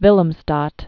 (vĭləm-stät)